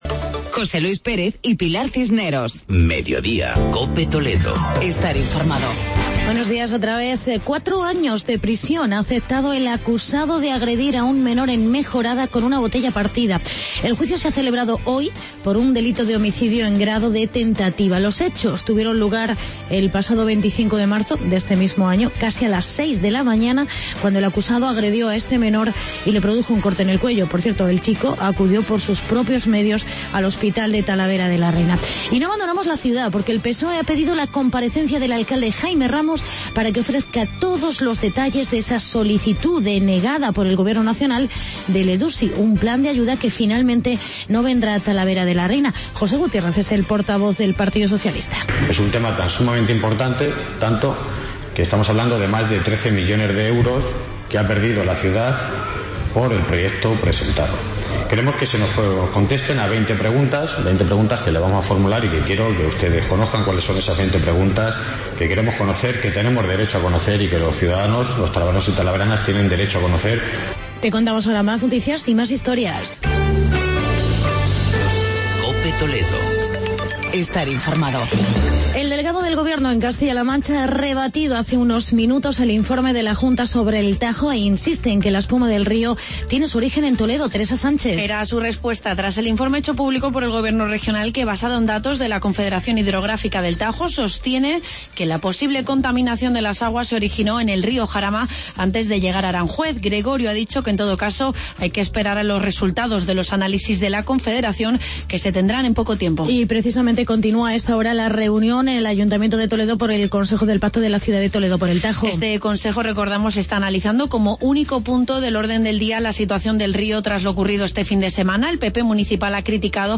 Actualidad y entrevista